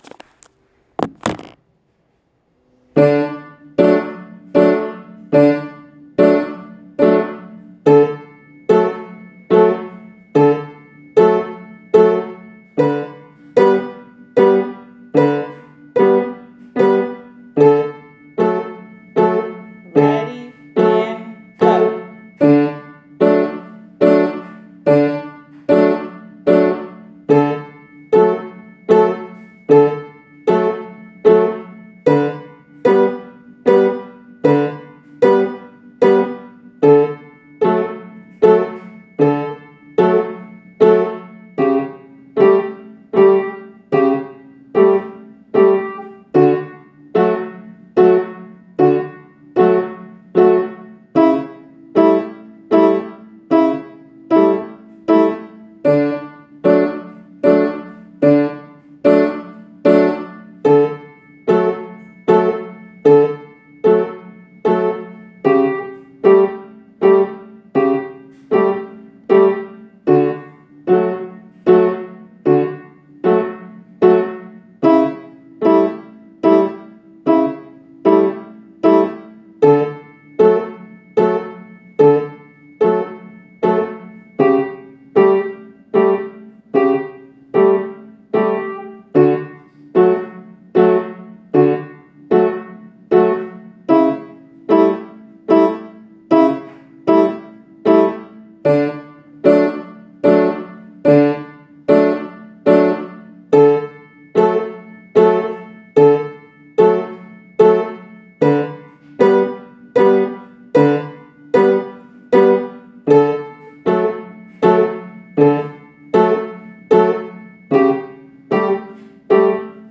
All Strings Tune (Piano Part) ALL STRINGS TUNE Comments